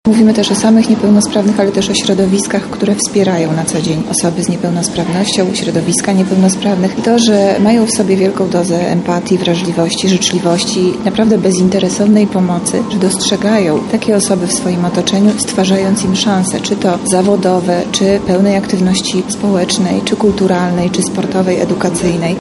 – mówi Monika Lipińska, zastępca prezydenta miasta.